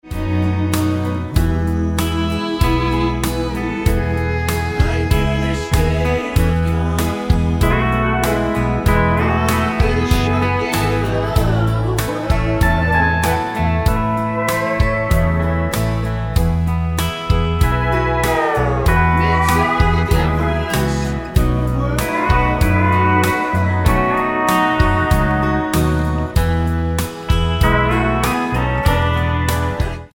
--> MP3 Demo abspielen...
Tonart:E-F mit Chor